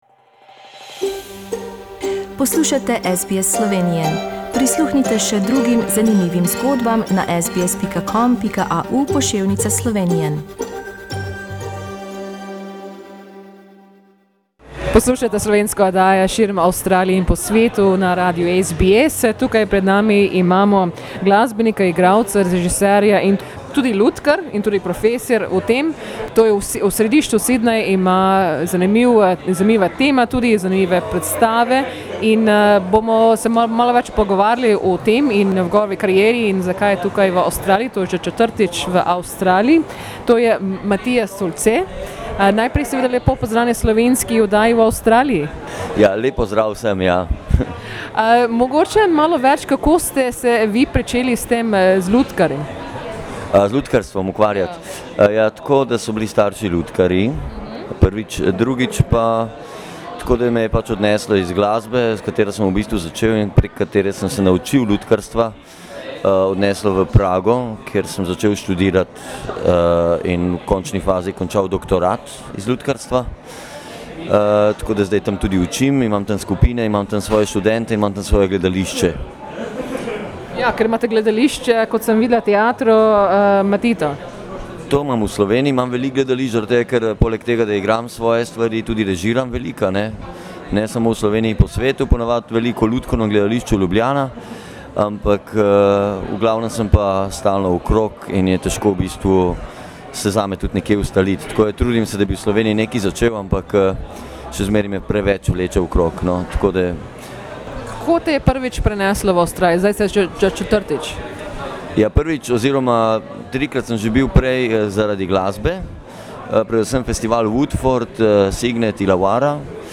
Z njim smo se pogovarjali v Sydneyu. https